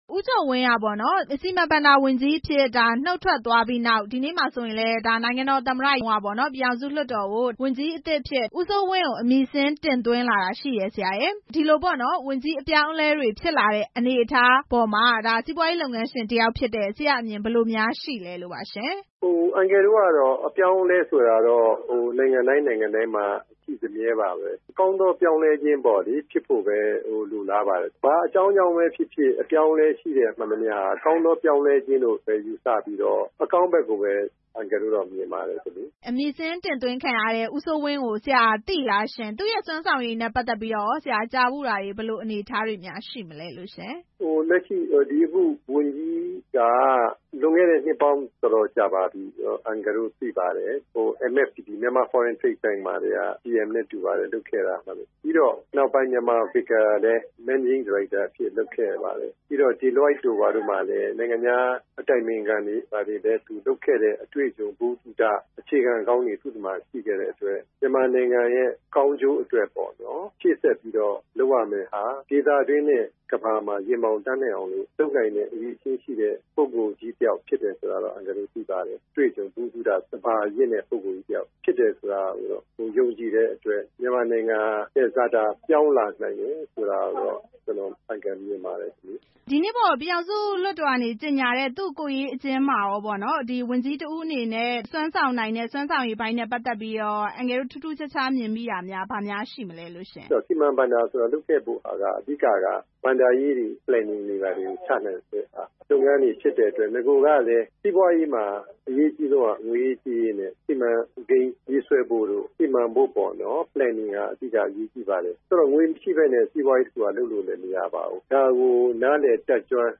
စီမံ ဘဏ္ဍာ ဝန်ကြီး အမည် တင်သွင်းမှုအပေါ် မေးမြန်းချက်